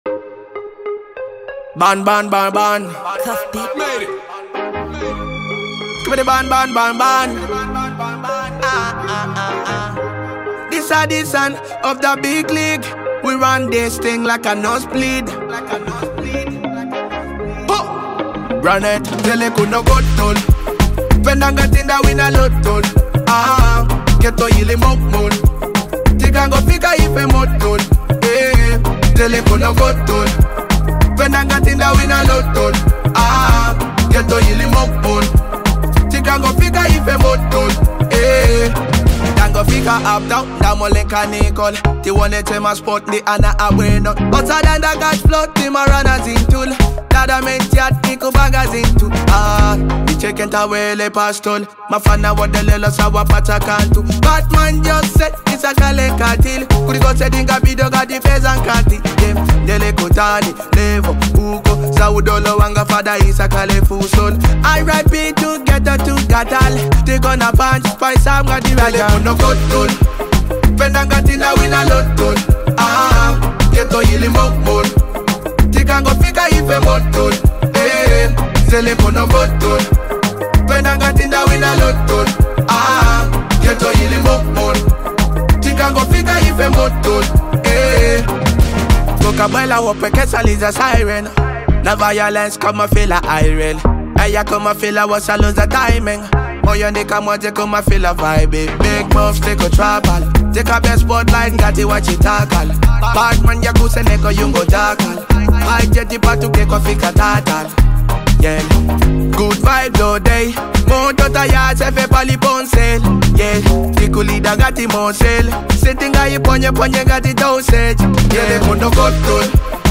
Known for his smooth vocals and infectious melodies